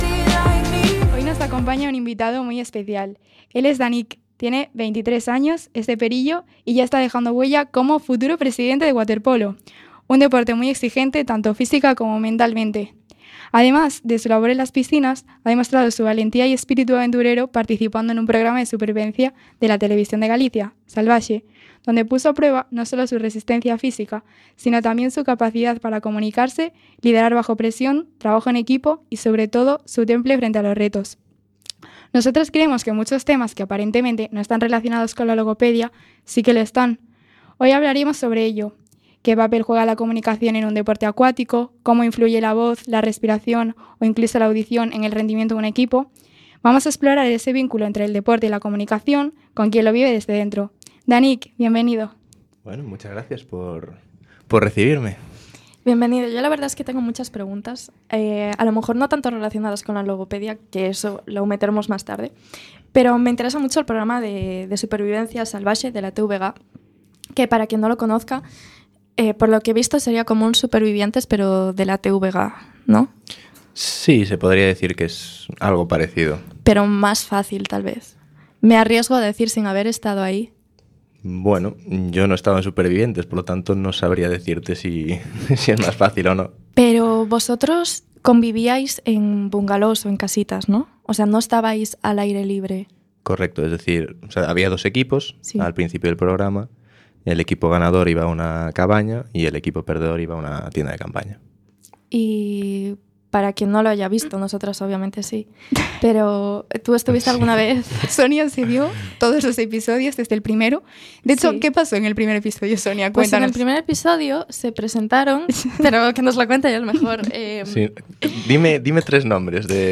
La logopedia está en todas partes, desde cómo aprendemos a leer hasta el pitido que escuchamos al salir después de pasar la noche en una discoteca. Entrevistas, comedia, divulgación...